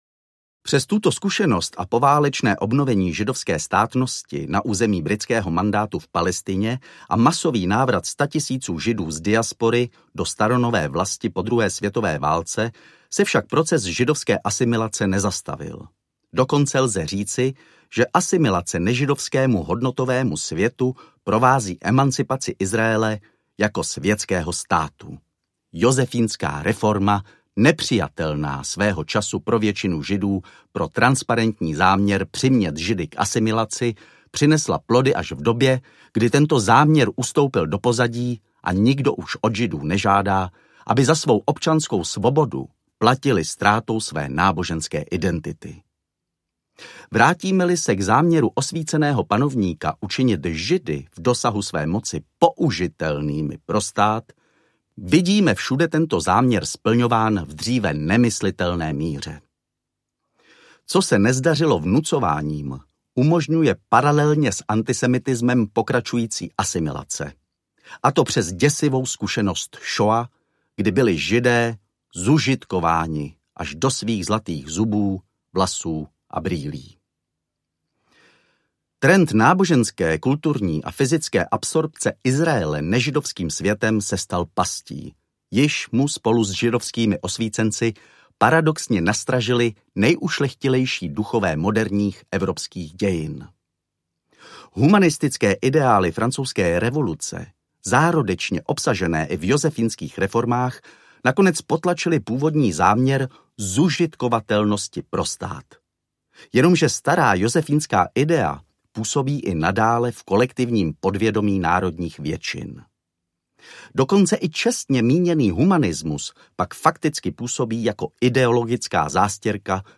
Ukázka z knihy
Vyrobilo studio Soundguru.